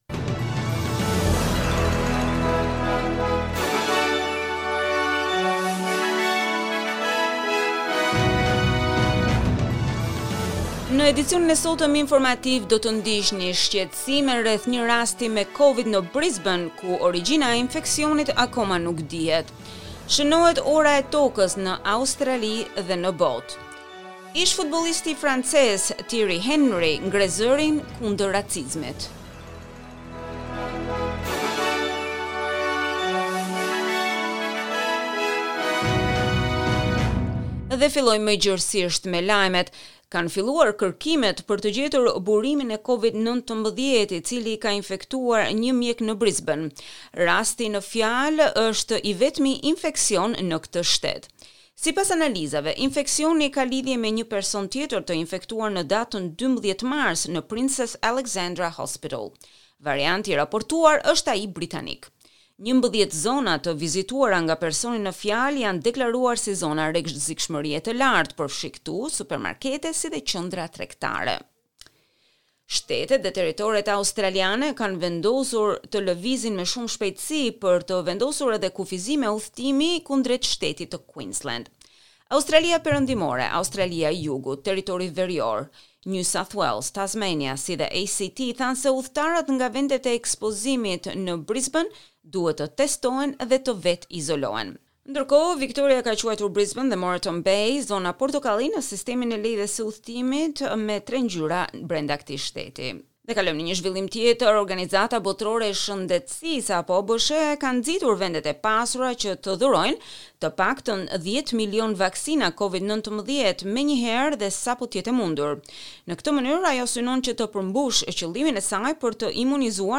SBS News Bulletin in Albanian - 27 March 2021